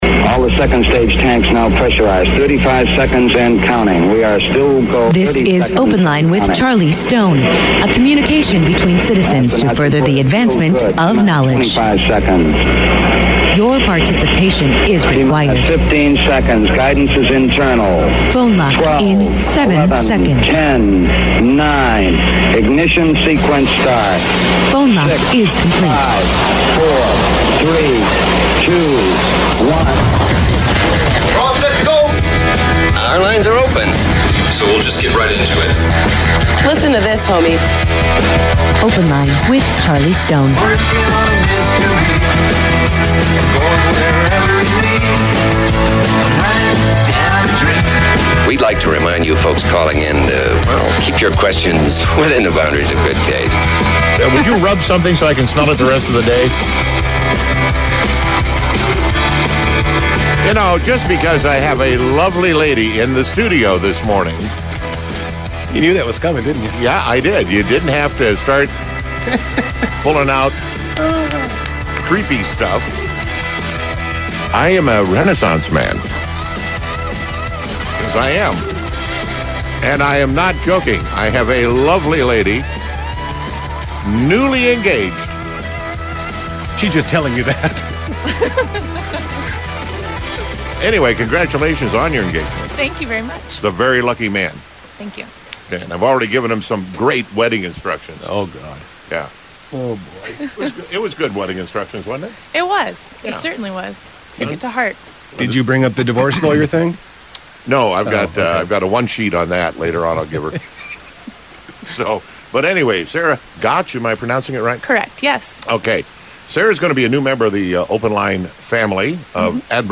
Talk radio at its finest!